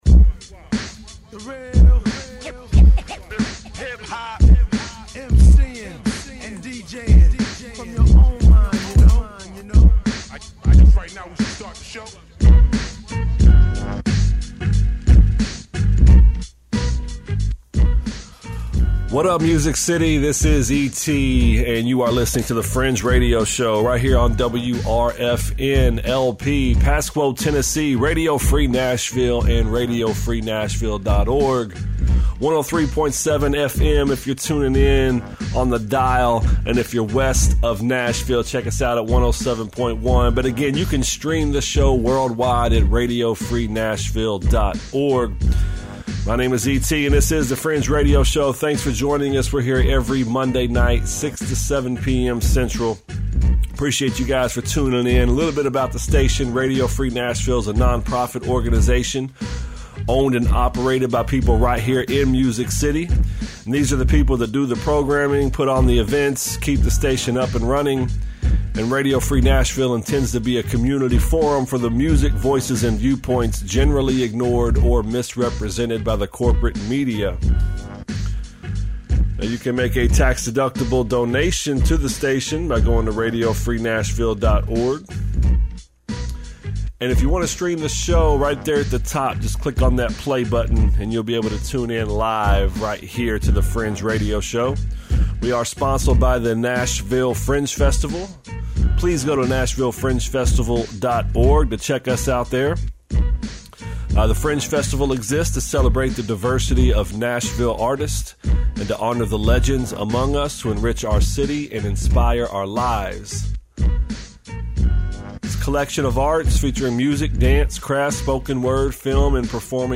old unreleased music